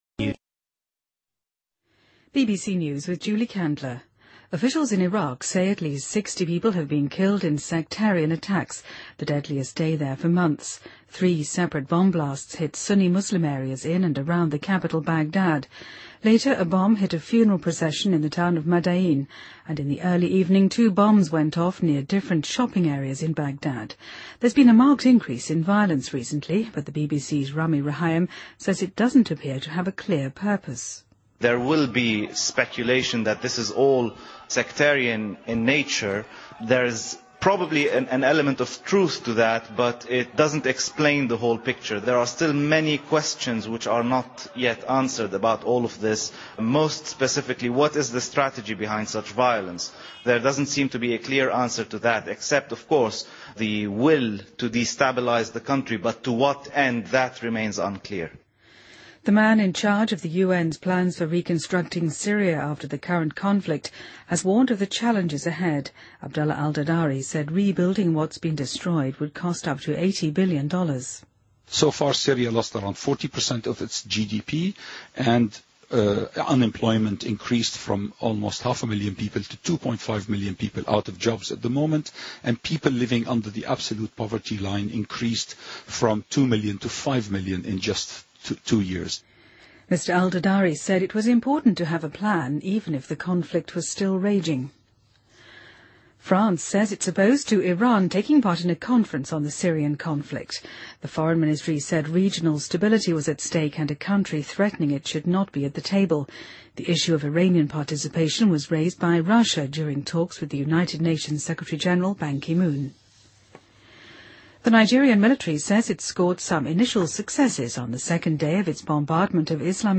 BBC news,2013-05-18